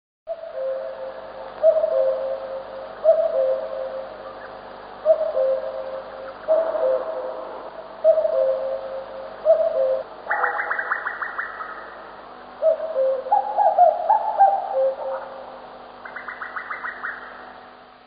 А здесь спрятана песнь кукушки
kukushka.mp3